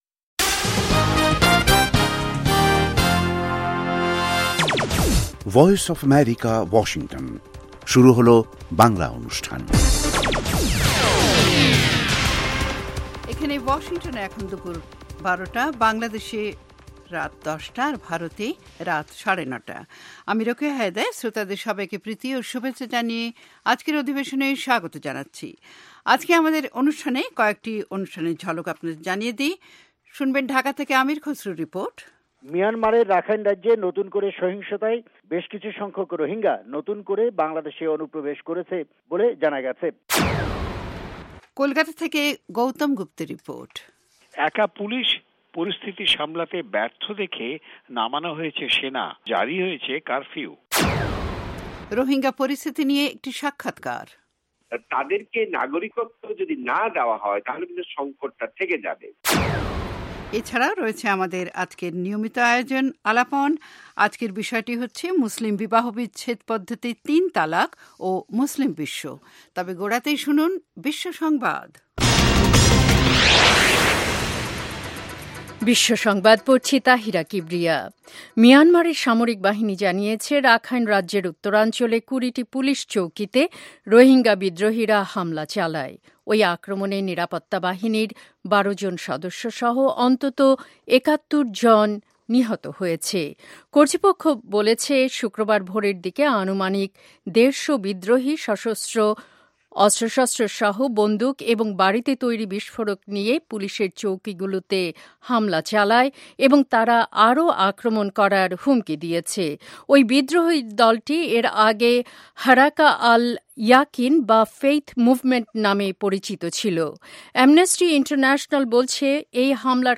অনুষ্ঠানের শুরুতেই রয়েছে আন্তর্জাতিক খবরসহ আমাদের ঢাকা এবং কলকাতা সংবাদদাতাদের রিপোর্ট সম্বলিত ‘বিশ্ব সংবাদ’, এর পর রয়েছে ওয়ার্ল্ড উইন্ডোতে আন্তর্জাতিক প্রসংগ, বিজ্ঞান জগত, যুব সংবাদ, শ্রোতাদের চিঠি পত্রের জবাবের অনুষ্ঠান 'মিতালী' এবং আমাদের অনুষ্ঠানের শেষ পর্বে রয়েছে যথারীতি সংক্ষিপ্ত সংস্করণে বিশ্ব সংবাদ।